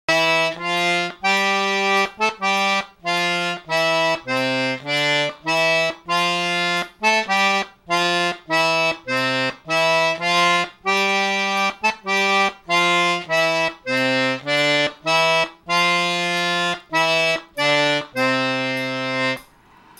When first learning the Cajun accordion, you may be better off learning one hand at a time.
Get the 10-button side nailed first,
Wafus_r_hand.wma